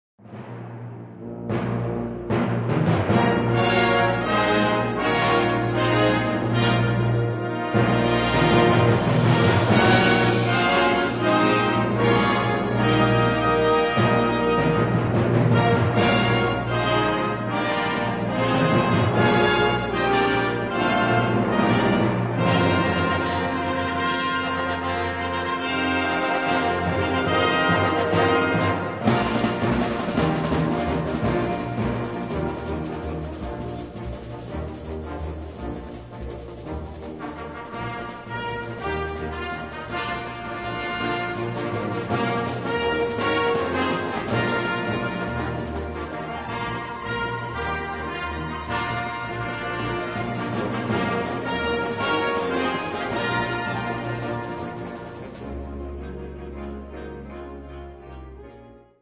Kategorie Blasorchester/HaFaBra
Unterkategorie Konzertmusik
Besetzung Ha (Blasorchester)
Es ist ein effektvolles und farbig instrumentiertes Werk.